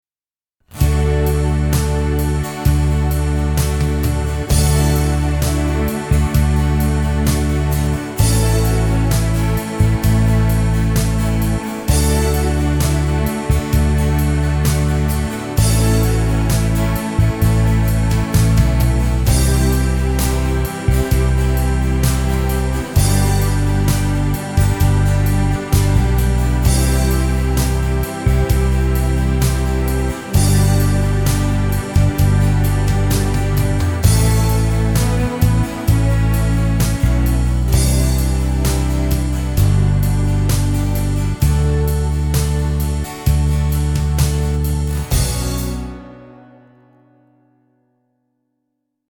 SOLO1 (with Acust)
130 bpm